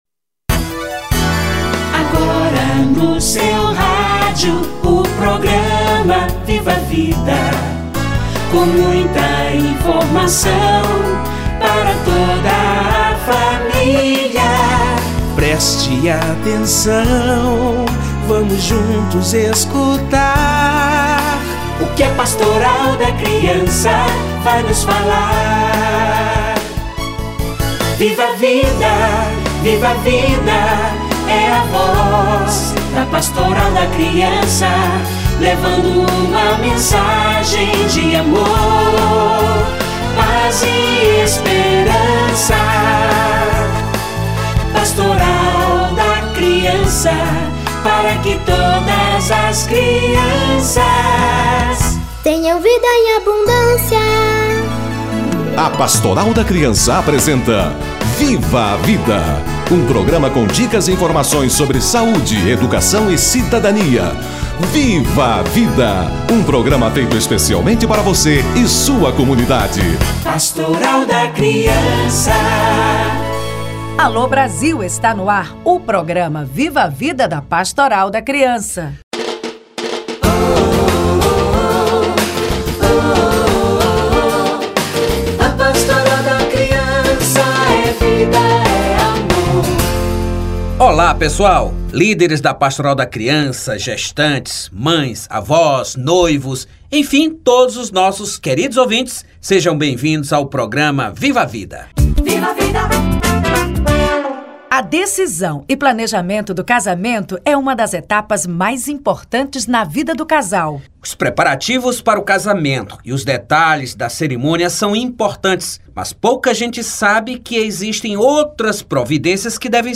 Preparação da mulher para gestação - Entrevista